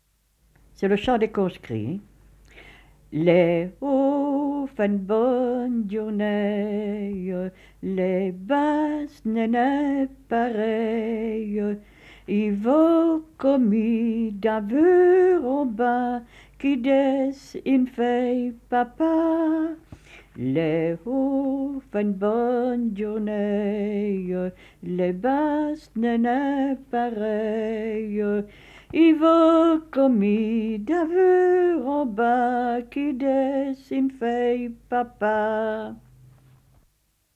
Genre : chant
Type : chanson de conscrit / tirage au sort
Lieu d'enregistrement : Vottem
Support : bande magnétique